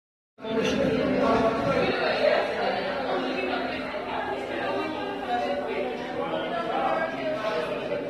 CALF after surgery in its pen.mp4